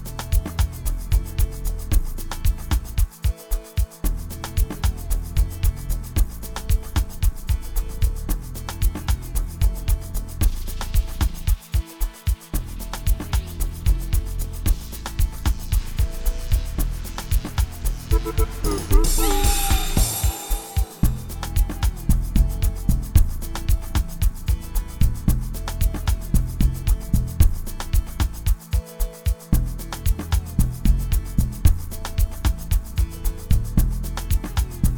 Жанр: Танцевальные / Африканская музыка